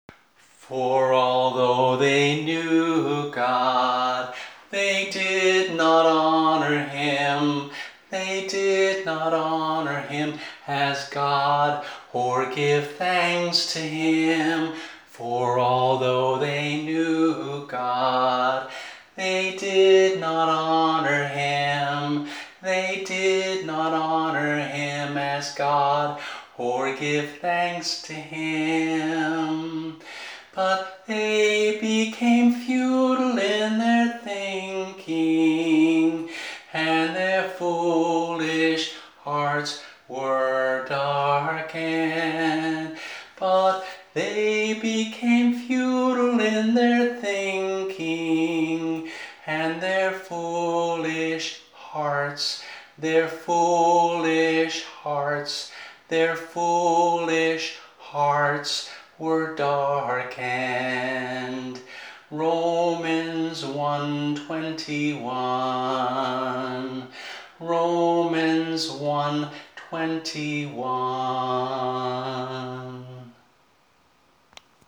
voice only